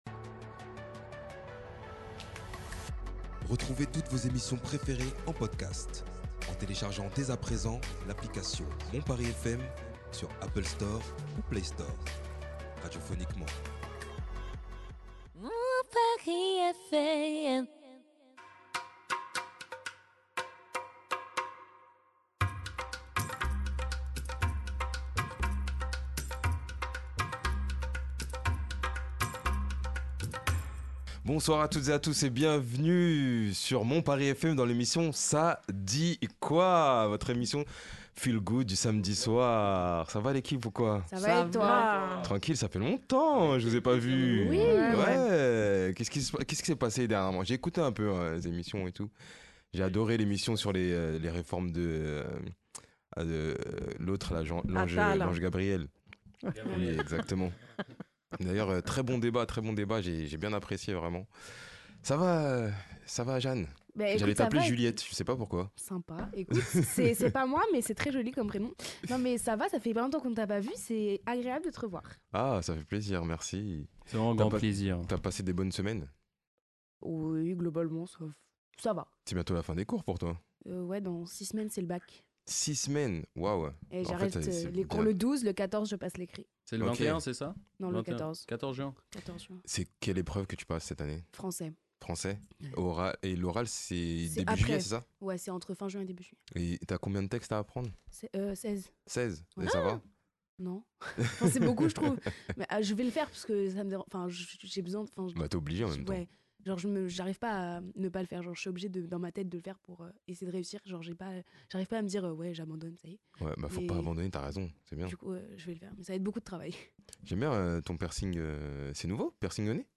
(Débat de la semaine)